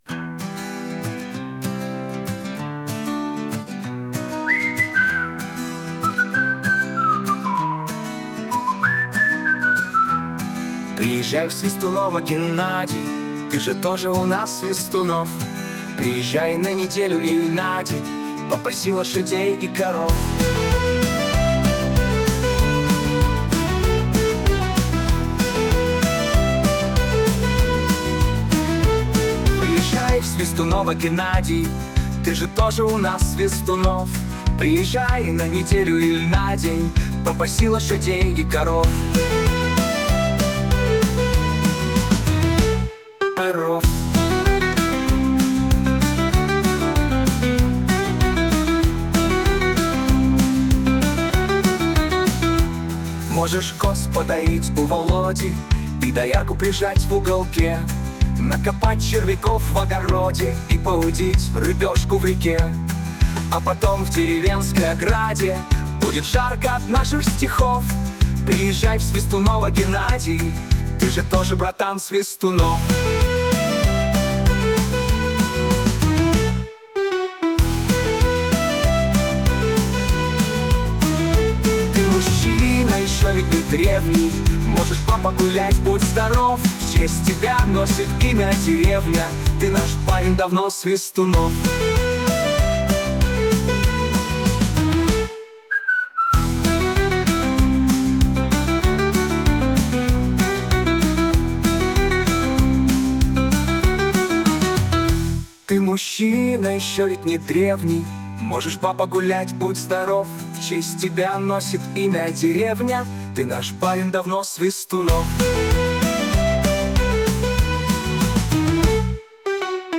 Шуточная песня